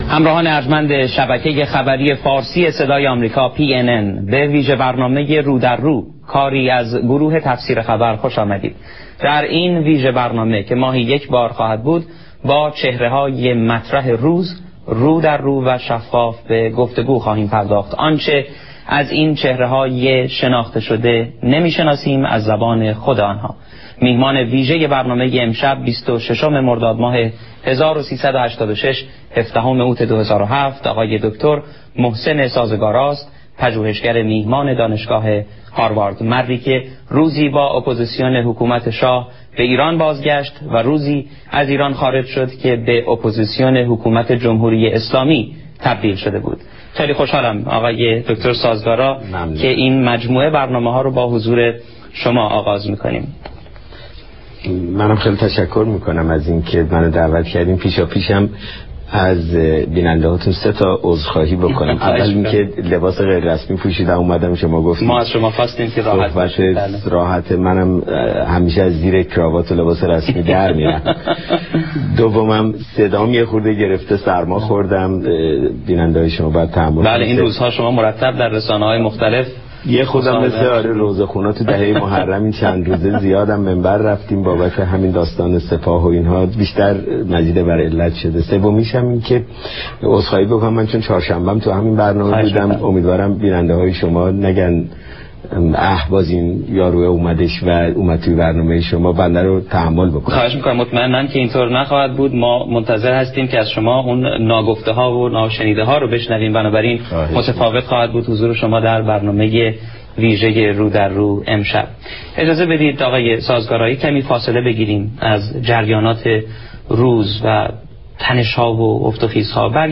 مصاحبه ها